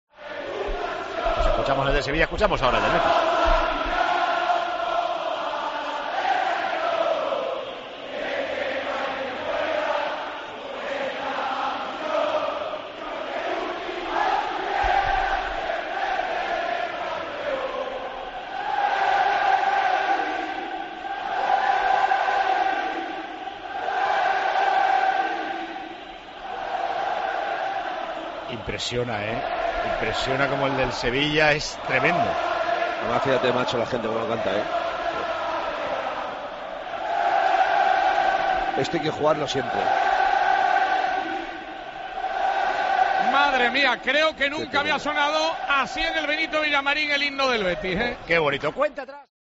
Así cantó el público del Villamarín el himno del Betis en el derbi por la Europa League
AUDIO: La afición bética deslumbró con su "Betis, Betis" al unísono.